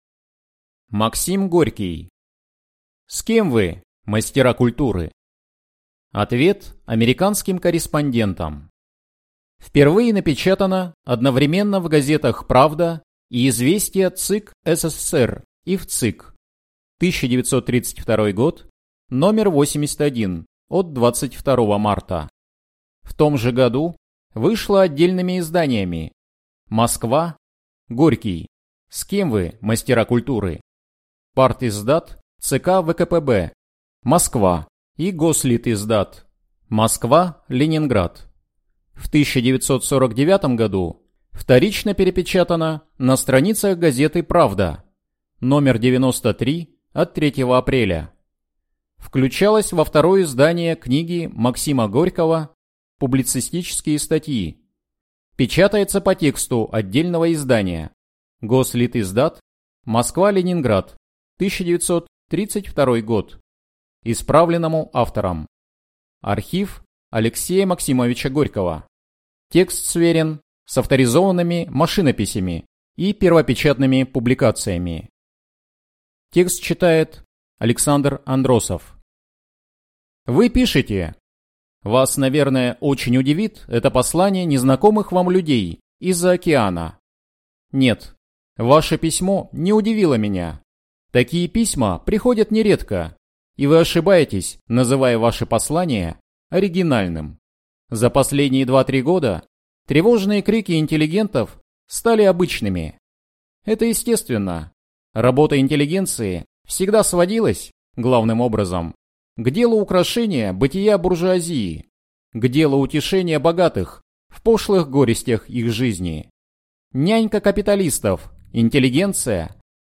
Aудиокнига С кем вы, «мастера культуры»?